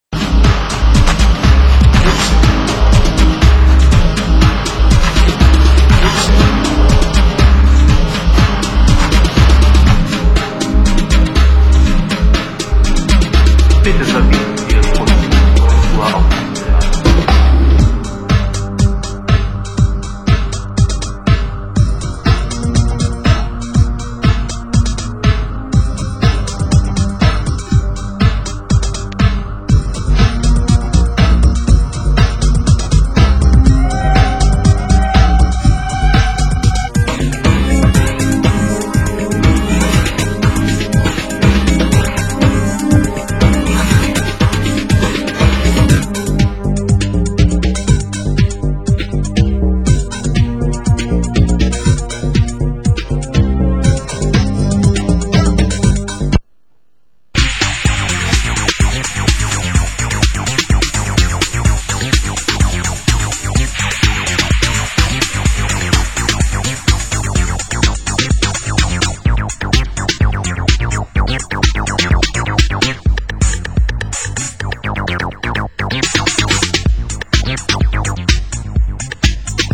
Genre: Acid House
industrial underground mix
industrial instru-mental mix